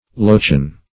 Search Result for " lochan" : The Collaborative International Dictionary of English v.0.48: Lochan \Loch"an\, n. [Gael.